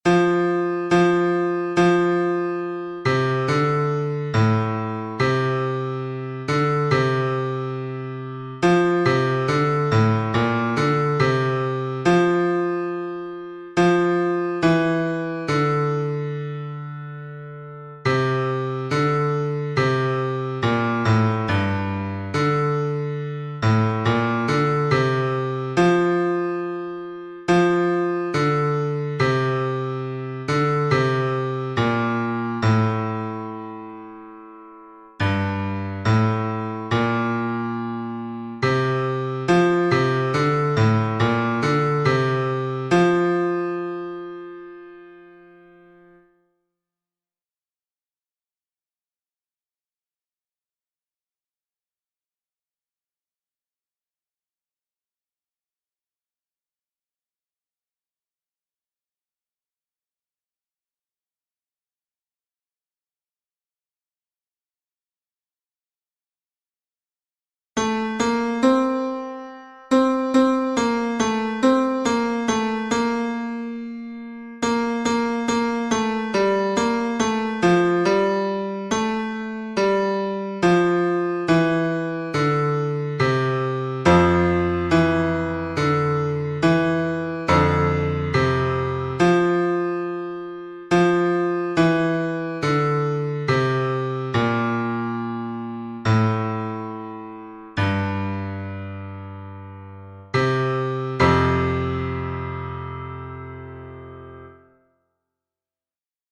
basses-mp3 11 mai 2022